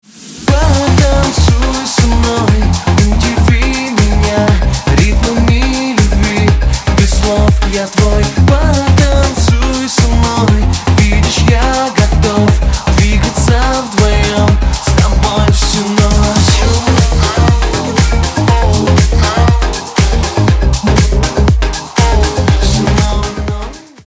• Качество: 128, Stereo
dance